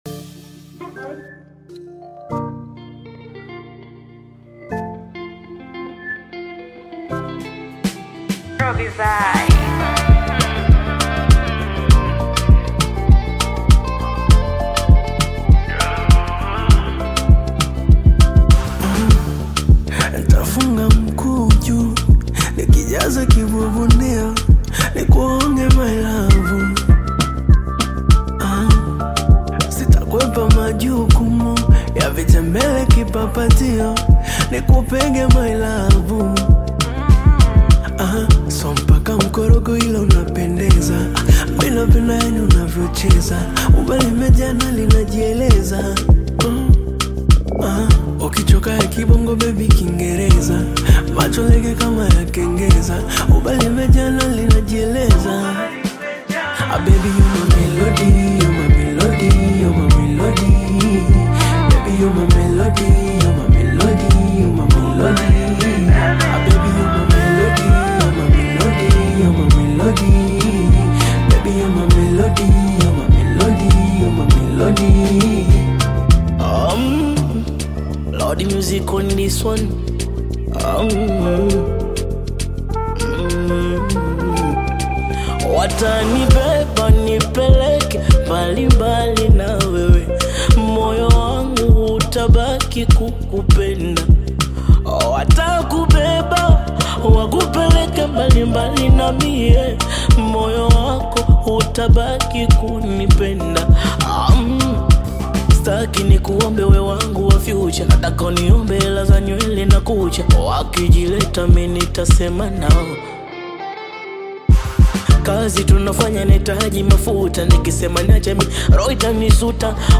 Tanzanian bongo flava artist